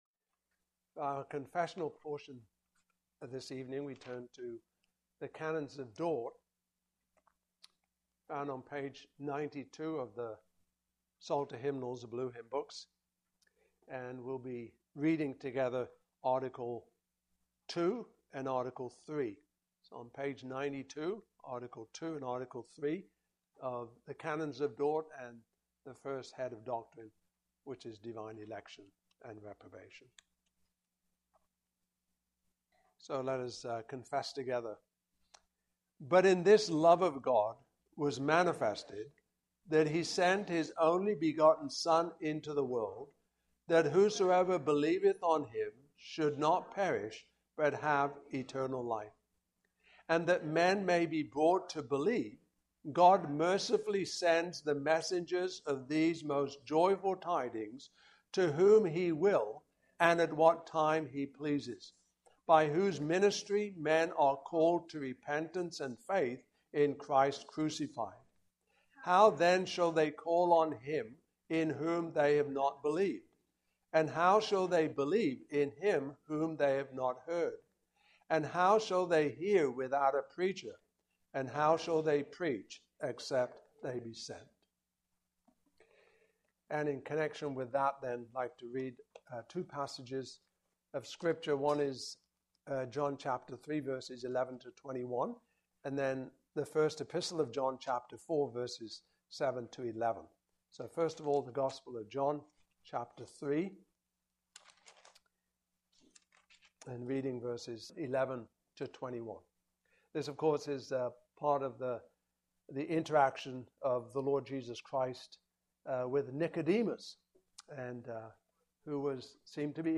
Canons of Dordt 2023 Passage: John 3:11-21, 1 John 4:7-11 Service Type: Evening Service Topics